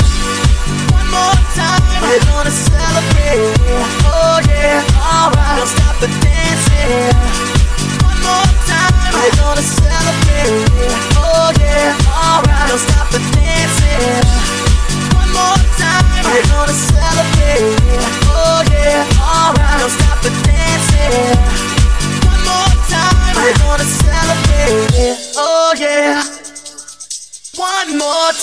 Genere: pop, funk, edm ,dance, disco,house, slap, remix